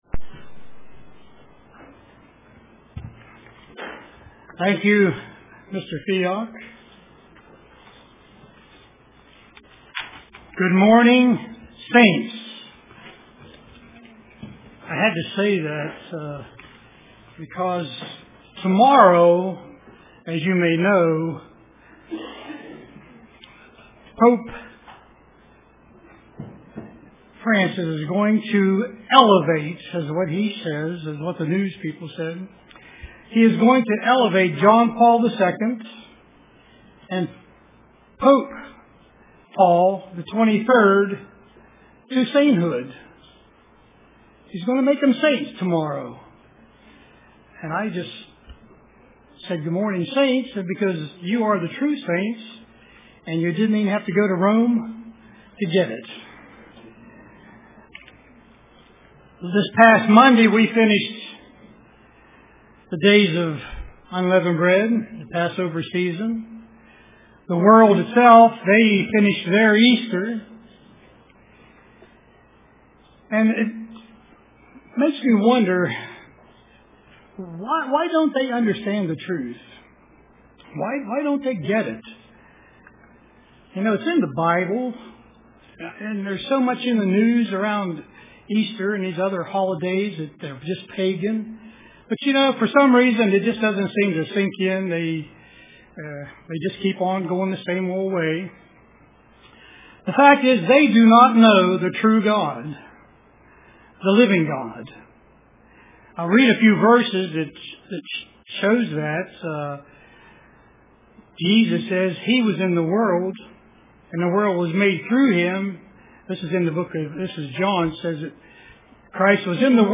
Print Looking at the Attributes of God UCG Sermon Studying the bible?